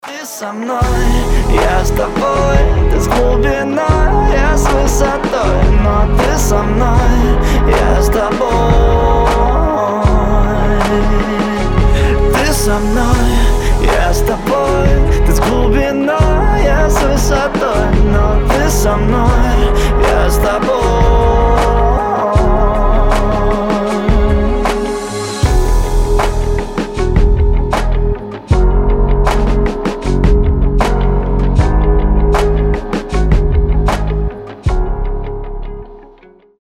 поп
мужской вокал
спокойные